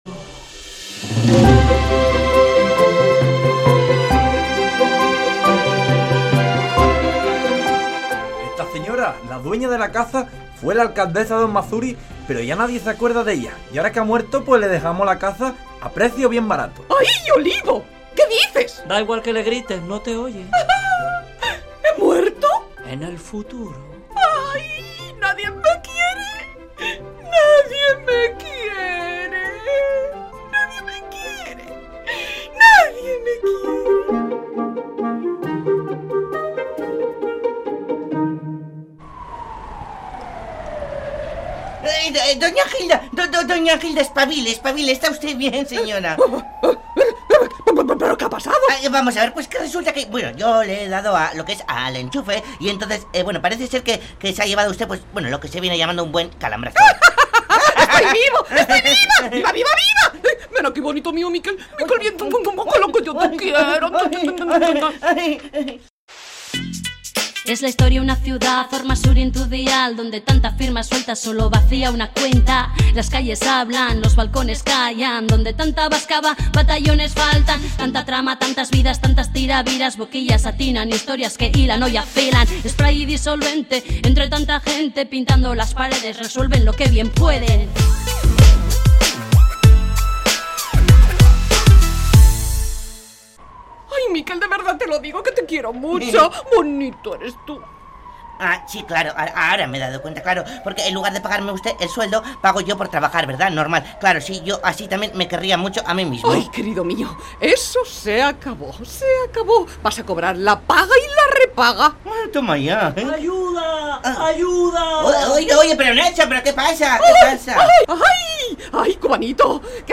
Audio: Entrega número 41 en el Graffiti de Radio Euskadi de la radio-ficción "Spray y Disolvente", escrita y dirigida por Galder Pérez